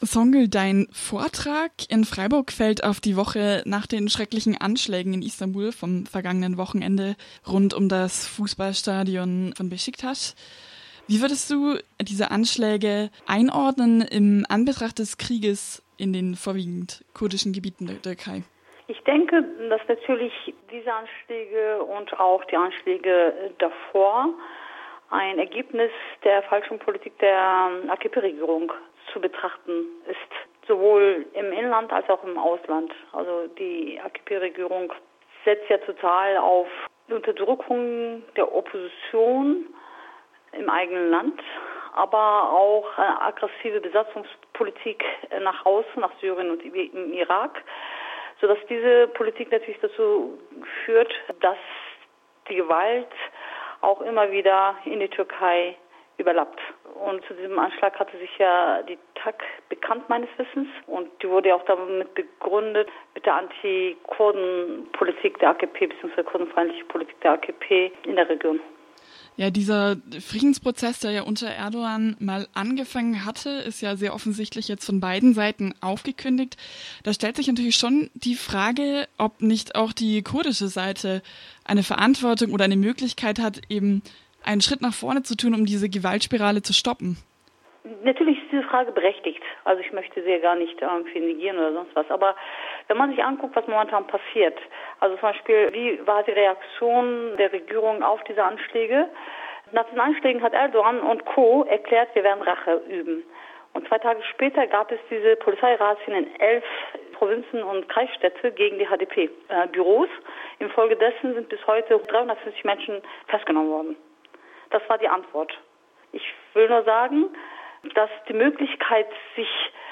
Vortrag „Hoffnung auf Frieden und Demokratie im Nahen Osten“ vom 14.12.2016 in Freiburg: Interview